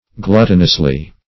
gluttonously.mp3